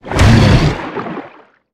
Sfx_creature_snowstalker_flinch_swim_03.ogg